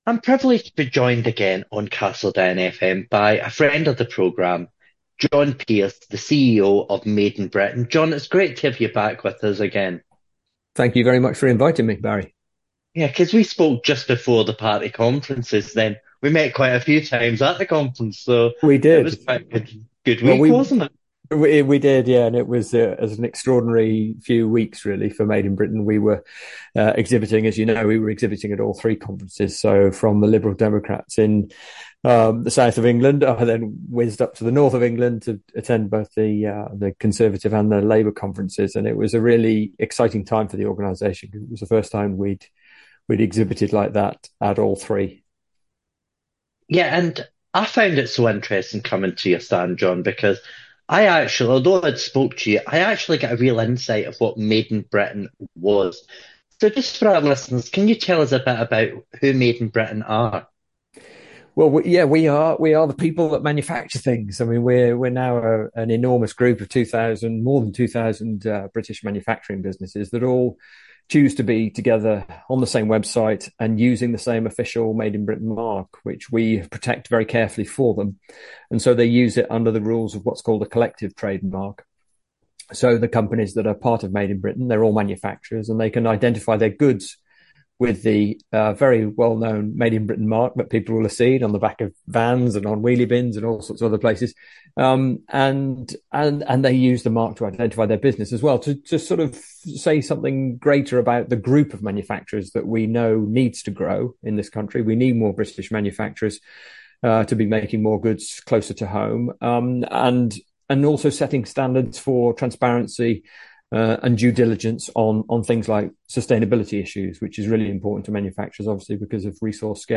CEO discusses UK manufacturing during in-depth radio interview - Made in Britain